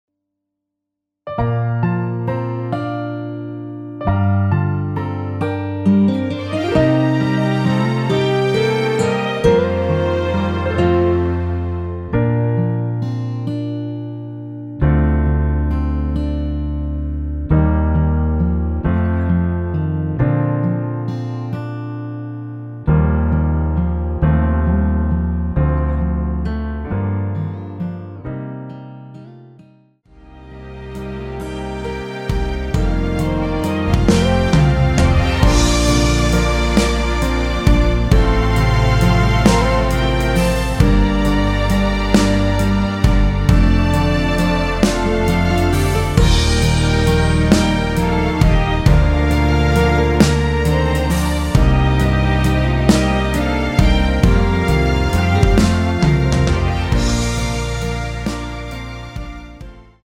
원키에서(-2)내린 MR입니다.
Bb
앞부분30초, 뒷부분30초씩 편집해서 올려 드리고 있습니다.
중간에 음이 끈어지고 다시 나오는 이유는